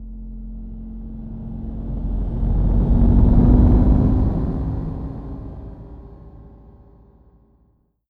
atterrissage.wav